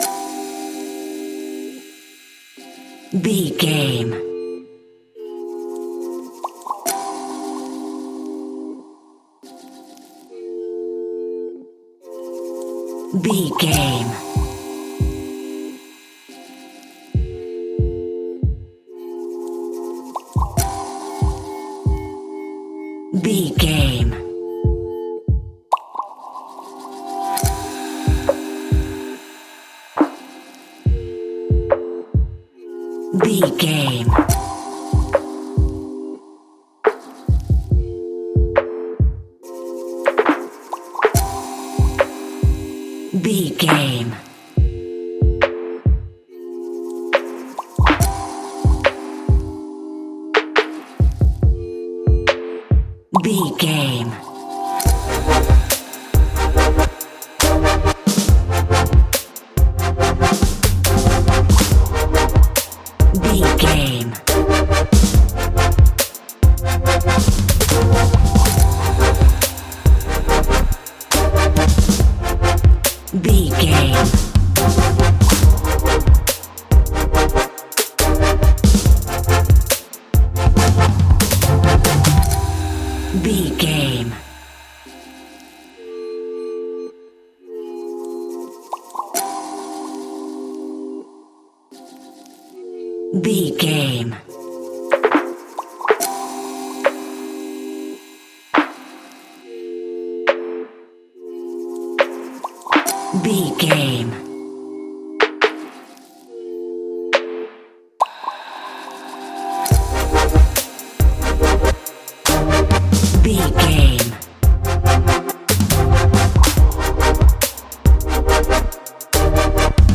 Aeolian/Minor
Fast
dreamy
bouncy
energetic
electric guitar
synthesiser
drum machine
percussion